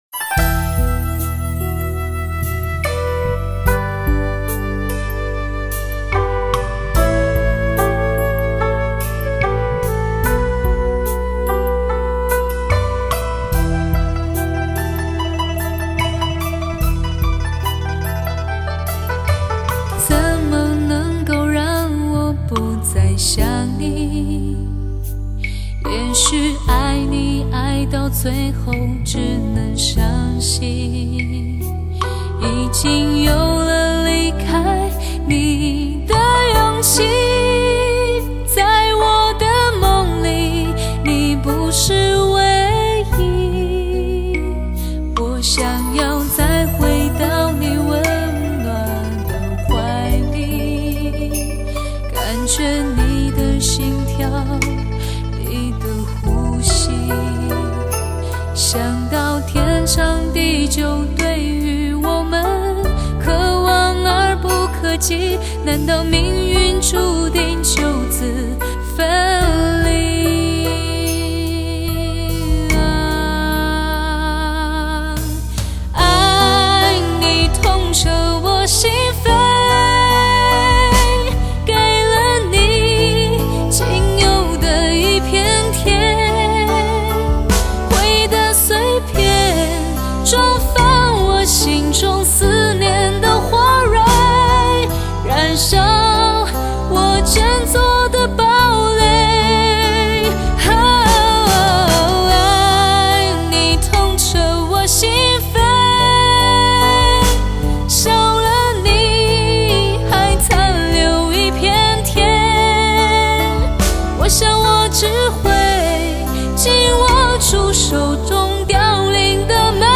贴在你耳朵上的柔情，让人无法拒绝。
音场宽广，音质纯正。